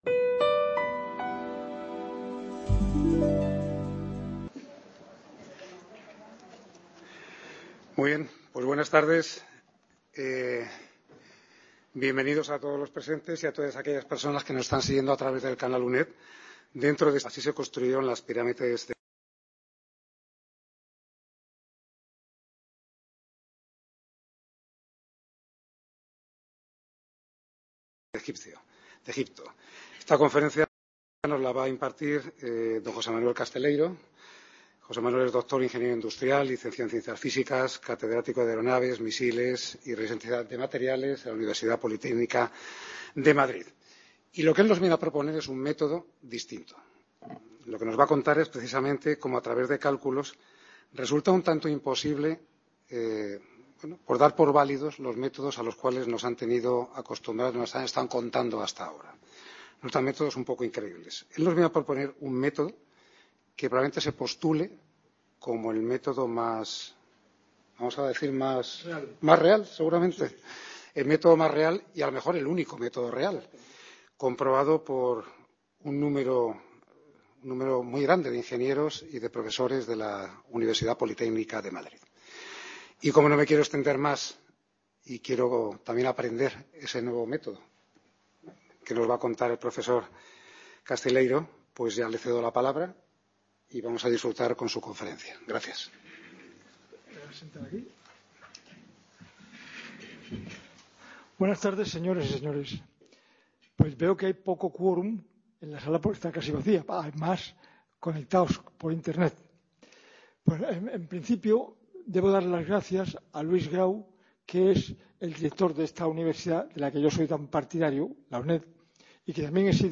El evento es gratuito y se podrá seguir presencialmente y de manera ONLINE. Esta conferencia trata de como se pudieron construir realmente las pirámides de Egipto y pretende deja claro dos cosas: La primera, es que ningún método de construcción de los que nos han venido ofreciendo diversos investigadores de hace 4.500 años, desde Herodoto hasta Marc Lehner, son absolutamente imposibles.